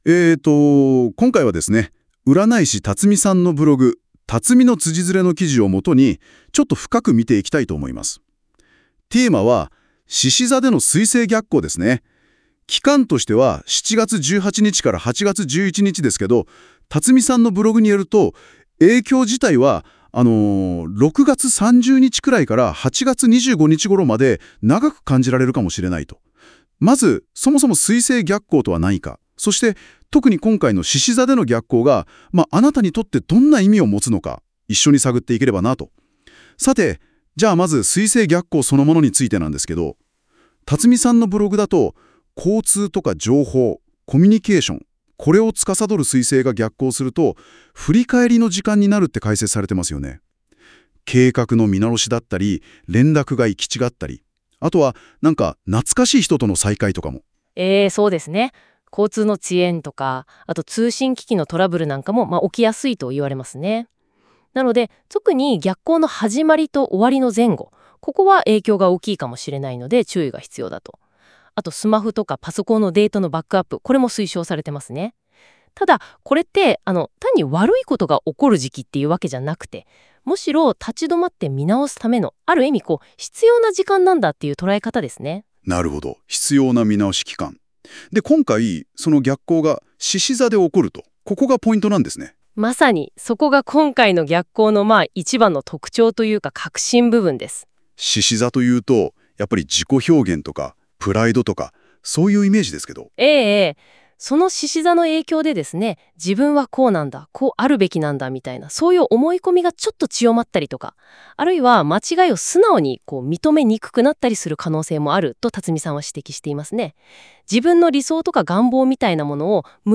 今回、実験的にnotebookLMで音声概要をしてみました。